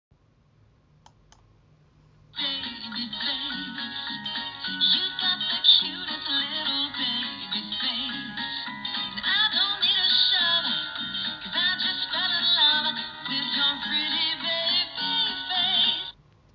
Grosser singender Folienballon Baby Girl
Mit einem Druck auf die markierte Stelle spielt der Ballon eine fröhliche Melodie – perfekt, um den besonderen Anlass gebührend zu feiern.
• 🎵 Musikfunktion: Spielt eine fröhliche Feier-Melodie – Aktivierung durch Drücken
Grosser-singender-Folienballon-Baby-Girl.mp3